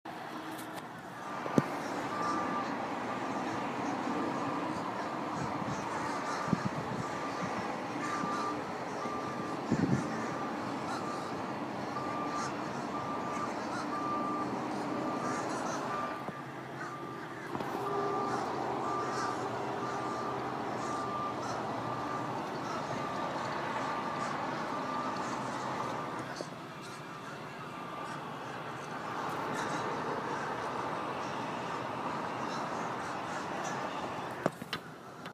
Field Recording
Location: 9th floor of Bill of Rights Sounds: wind blowing, birds, truck backing up, cars passing, window closing